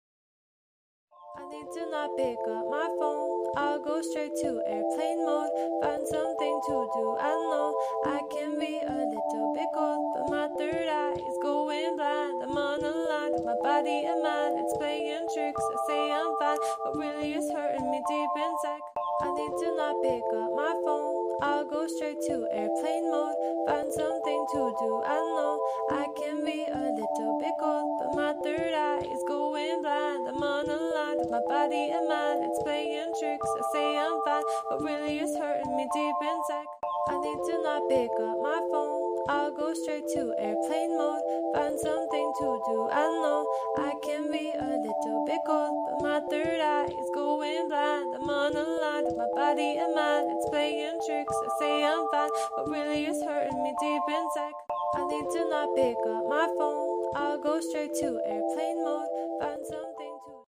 #8d #8dmusic 60 seconds 72 Downloads I believe in us>>